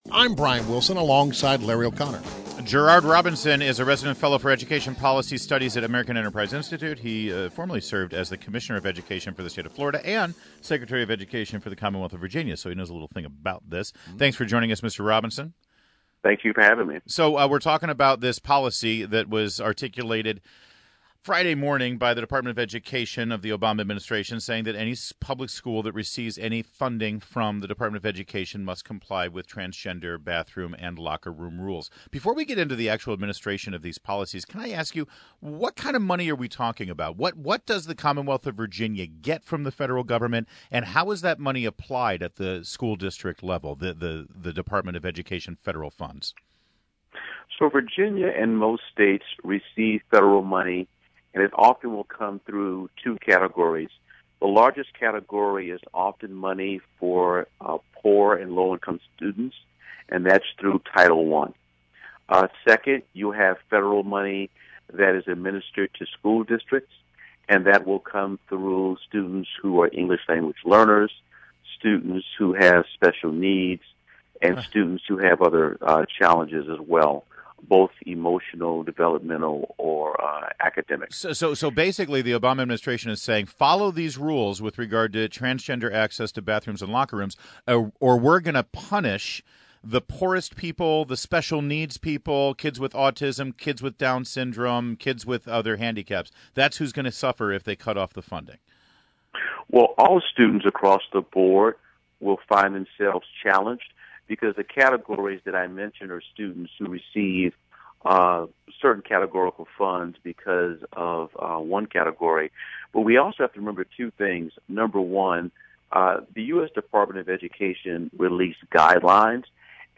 WMAL Interview: AEI's Gerard Robinson 05.16.16
INTERVIEW - GERARD ROBINSON - Resident Fellow, Education Policy Studies at American Enterprise Institute and formerly served as COMMISSIONER OF EDUCATION FOR the State of FLORIDA and SECRETARY OF EDUCATION for the Commonwealth of VIRGINIA